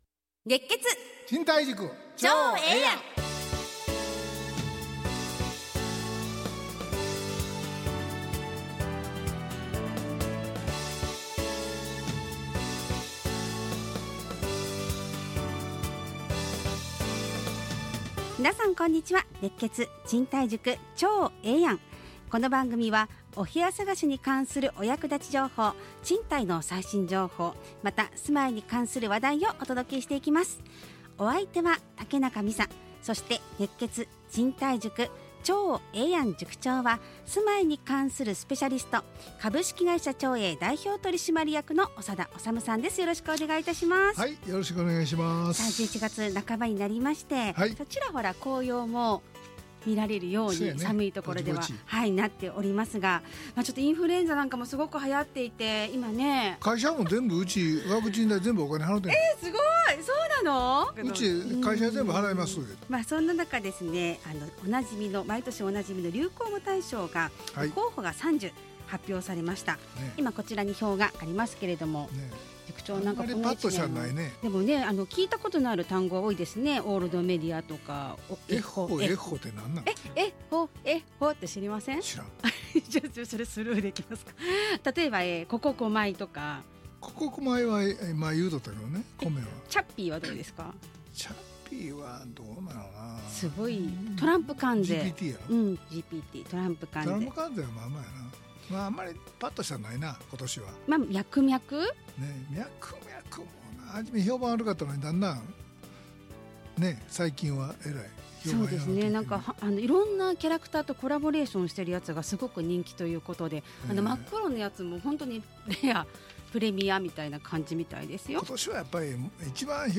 ラジオ放送 2025-11-14 熱血！